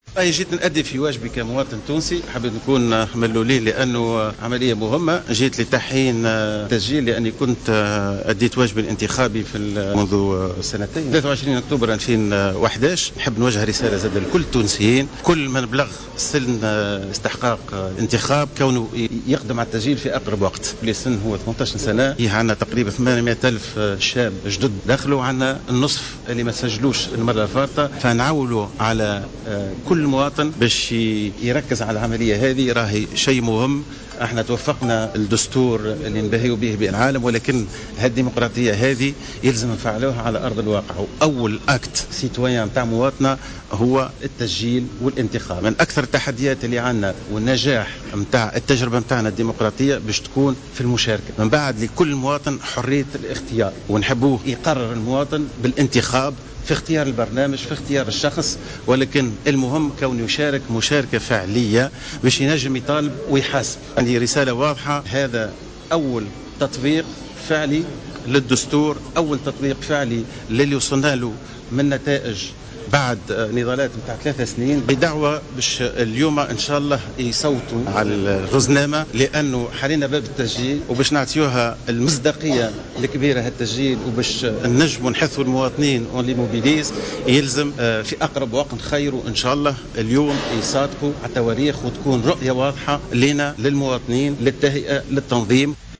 اعتبر رئيس الحكومة المؤقتة مهدي جمعة في تصريح لجوهرة أف أم اليوم الاثنين أن أول اختبار تواجهه تونس لتقييم نجاح تجربتها الديمقراطية يتمثل في الإستحقاق الانتخابي القادم والذي يمرّ نجاحه عبر المشاركة الفعلية والتسجيل في الانتخابات.